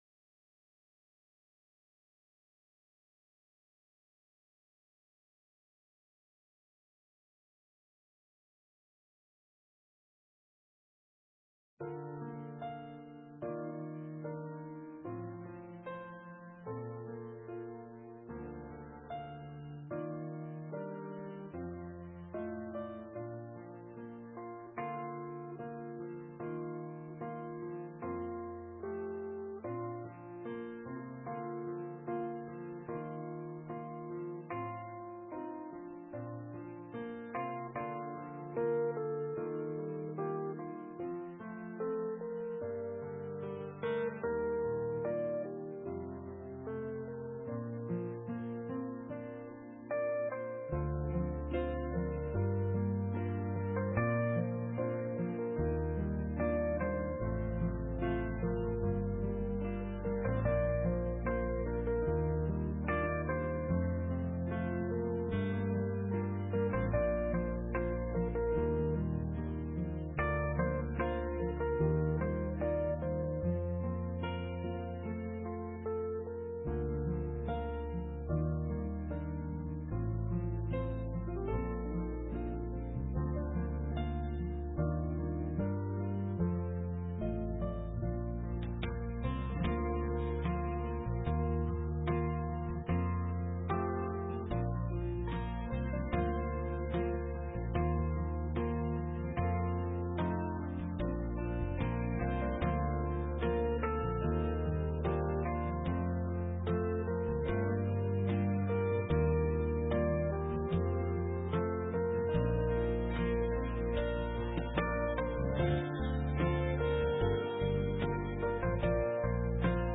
Sermon:From Spiritual Slump to Vitality part 3 - St. Matthews United Methodist Church
2021 We are RECEIVED as the Beloved of God The actual worship service begins 15 minutes into the recordings.